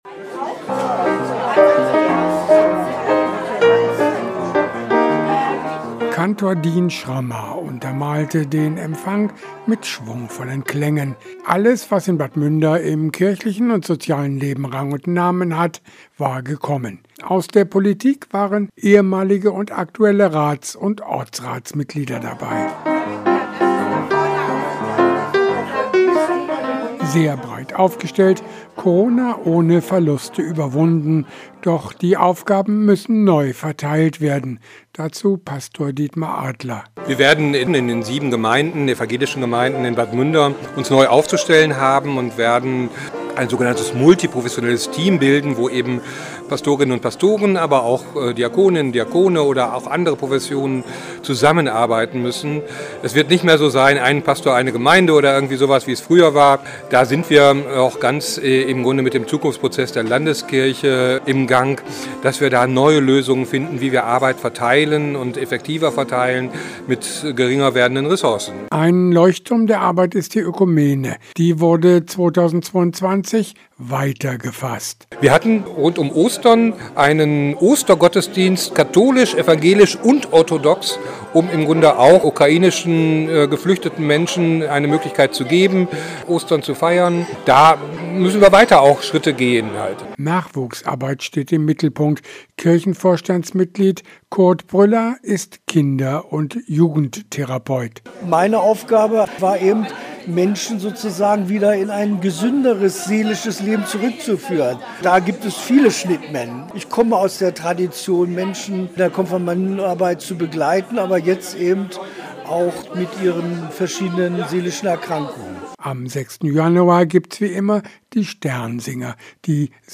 Bad Münder: Neujahrsempfang in der Petri-Pauli-Kirche – radio aktiv
Aktuelle Lokalbeiträge